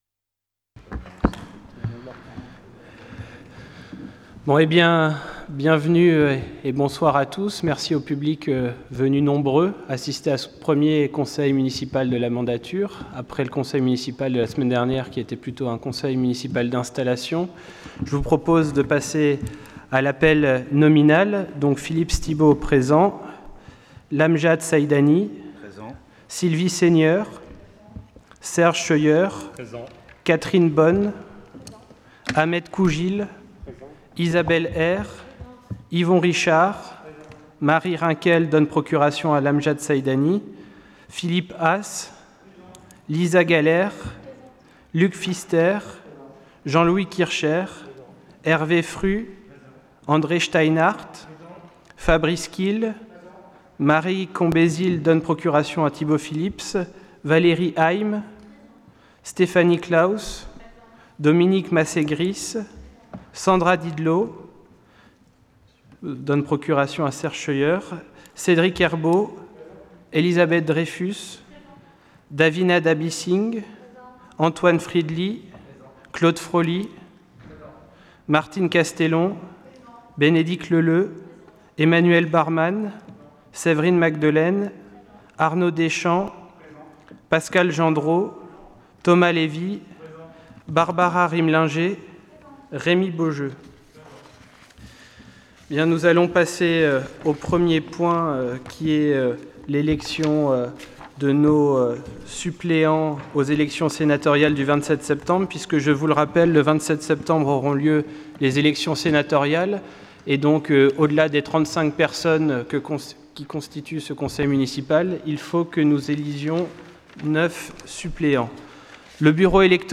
Conseil Municipal du 04 juillet 2020